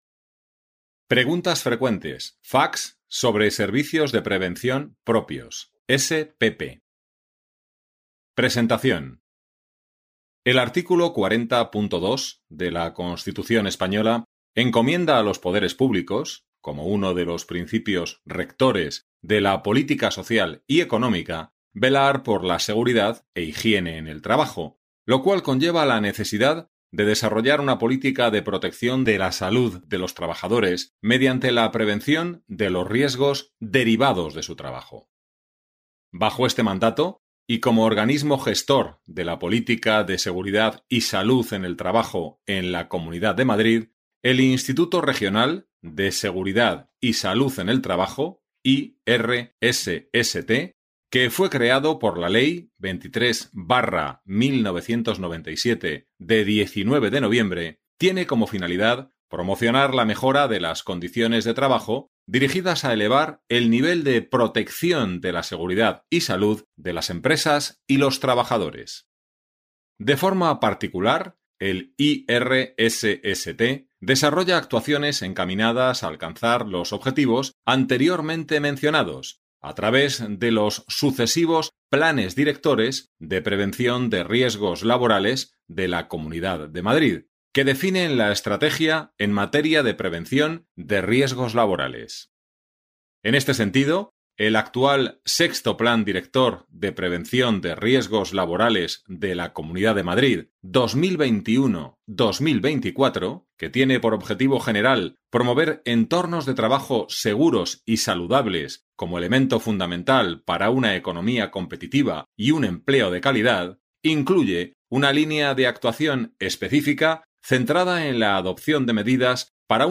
Audiolibro